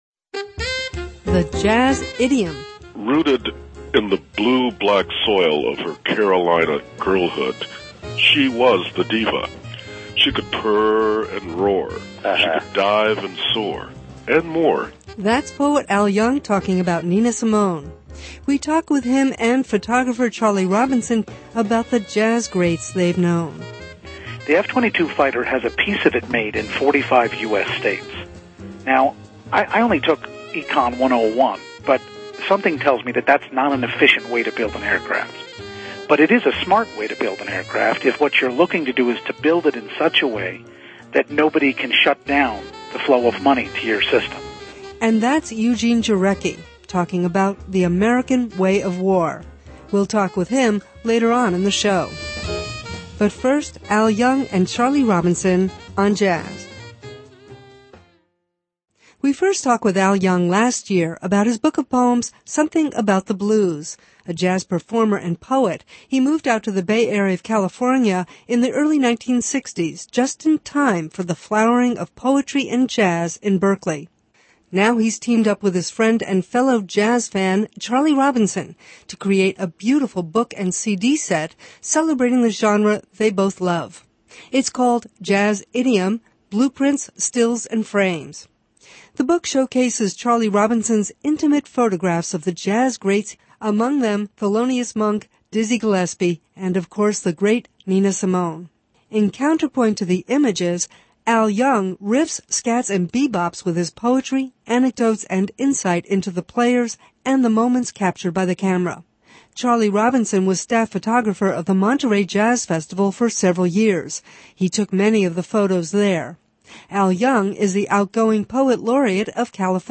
[sniplet jazz ad] And we hear excerpts from music by the book’s subjects.